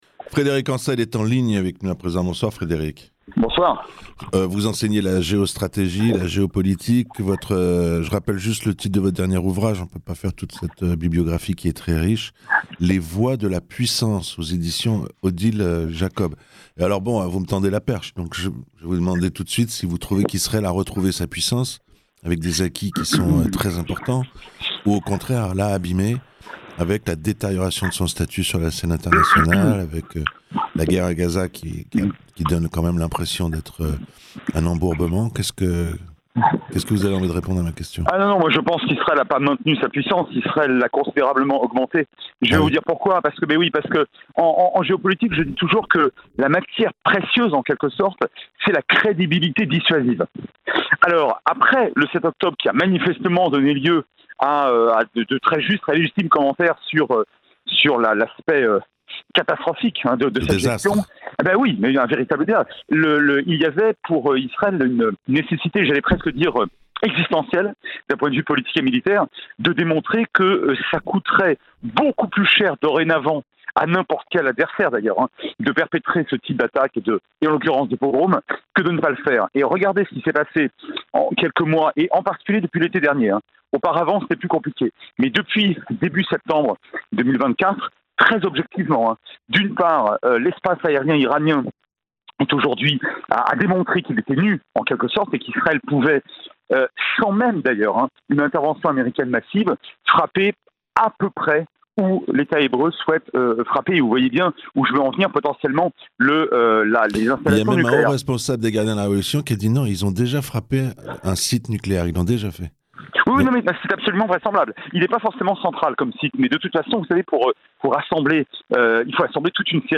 Dans un entretien accordé à Radio Shalom, Frederic Encel, docteur en géopolitique nous explique comment lsraël, depuis le début de sa riposte face au Hamas et sa guerre face au Hezbollah, a décuplé sa puissance sur la scène internationale.